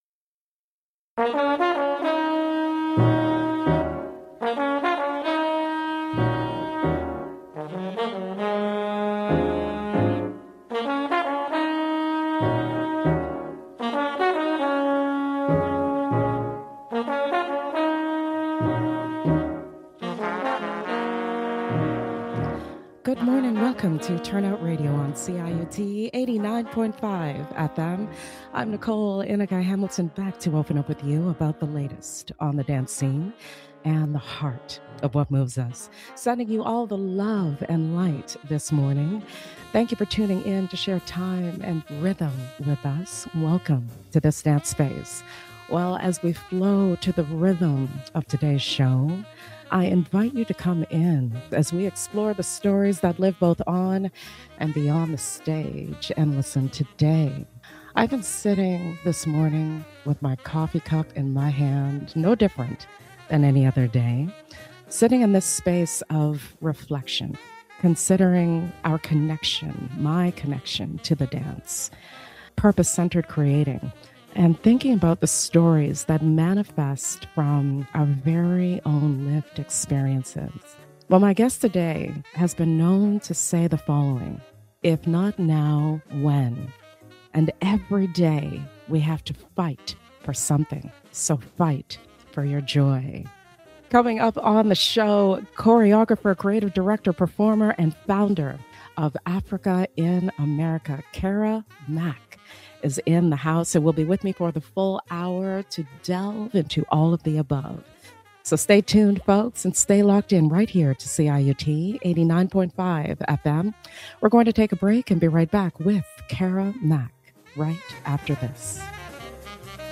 ive on CIUT 89.5 FM